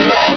Cri de Draby dans Pokémon Rubis et Saphir.